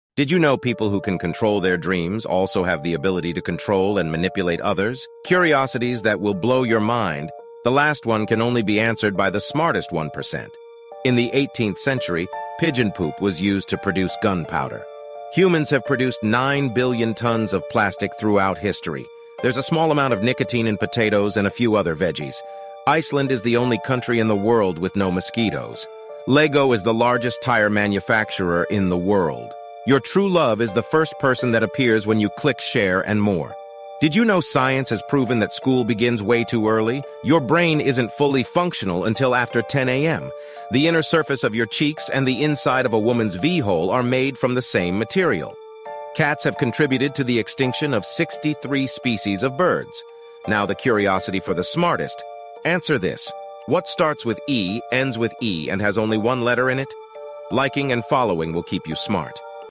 More AI fun, this time using GPT4, python and voice generation to make a random fun facts video